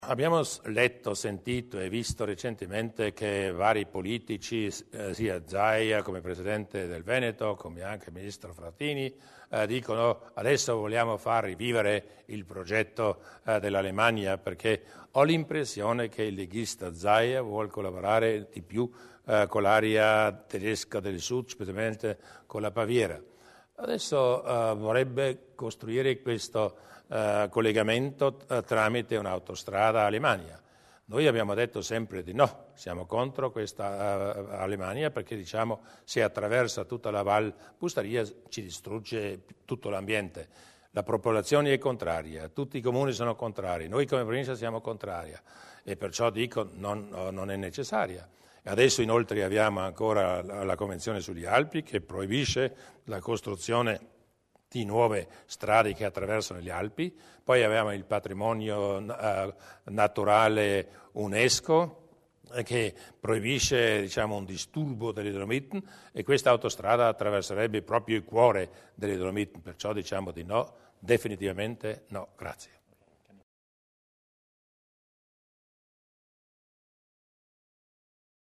Di seguito alcune delle decisioni assunte dalla Giunta provinciale nella seduta di oggi (19 luglio) e illustrate dal presidente Luis Durnwalder nella successiva conferenza stampa.